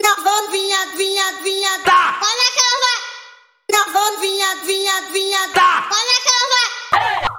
Energatic Brazilian Funk Female & Kid Vocals